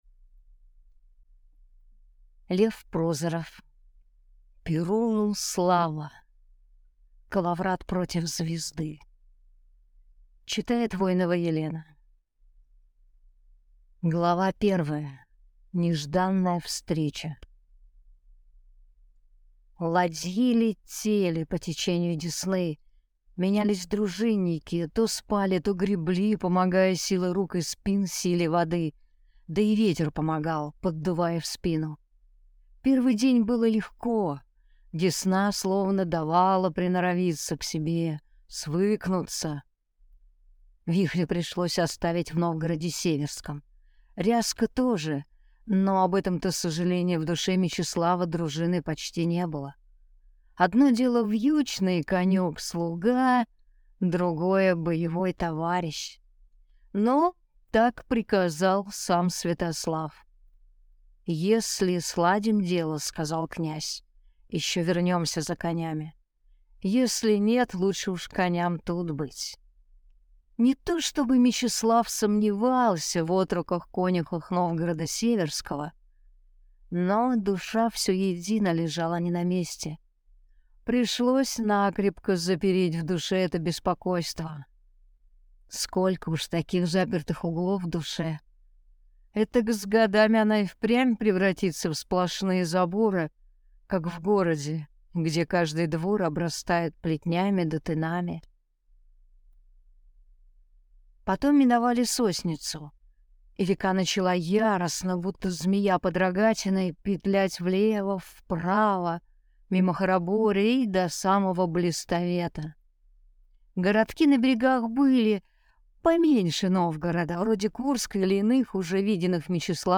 Аудиокнига Перуну слава! Коловрат против звезды | Библиотека аудиокниг